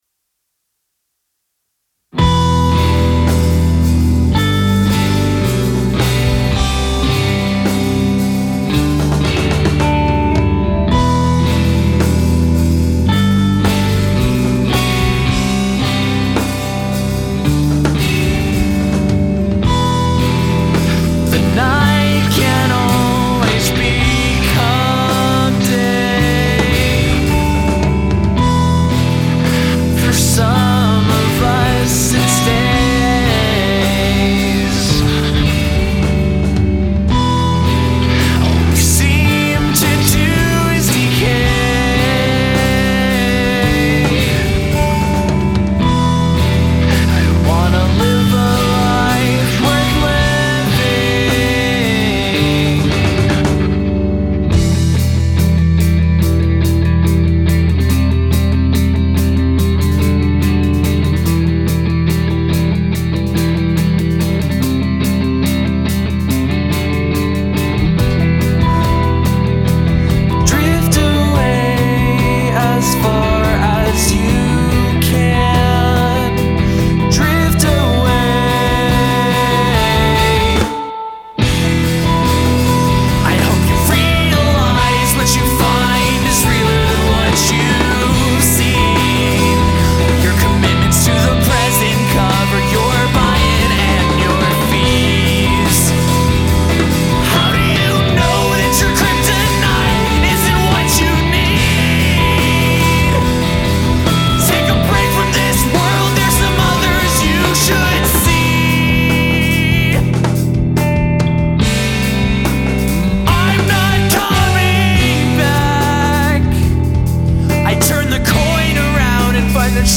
Most of my focus was on the drums and vocals.